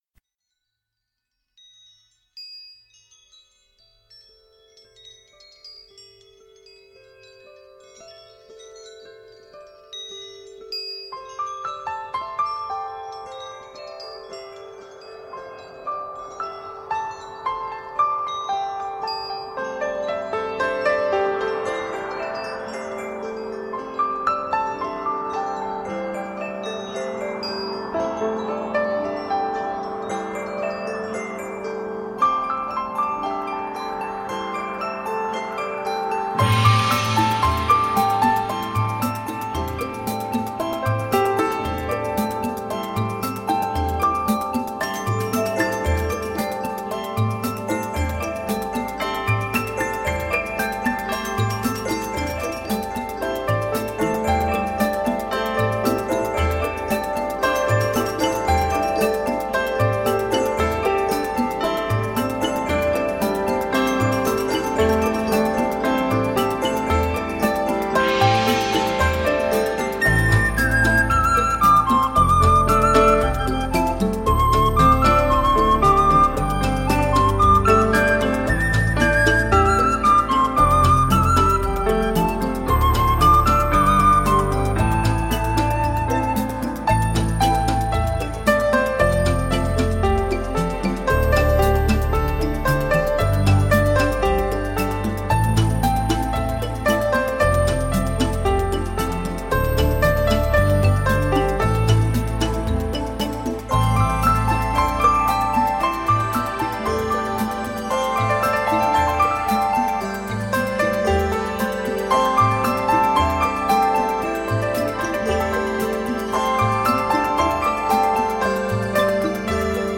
New age Медитативная музыка Нью эйдж